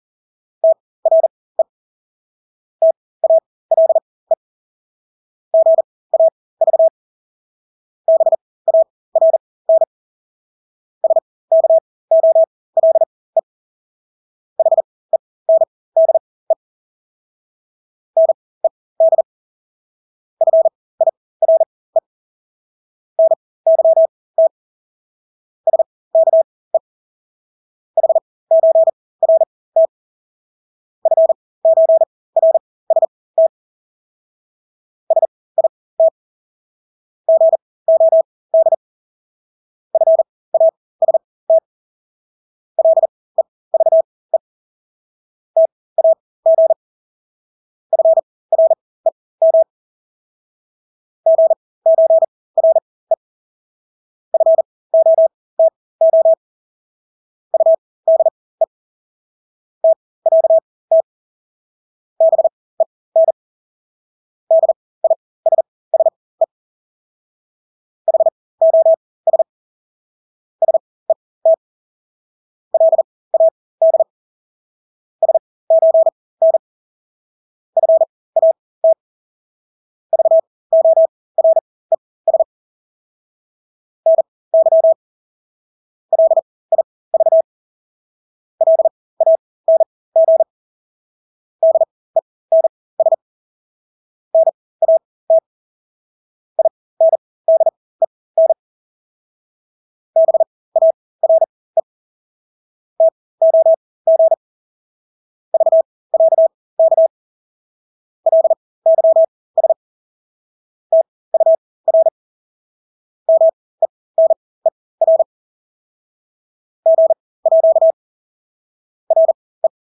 Hastighed: 40 - 44 wpm